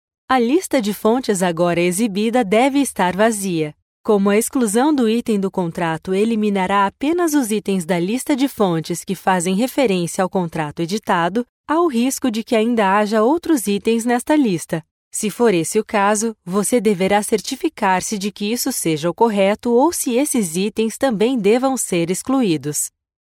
Locutoras brasileñas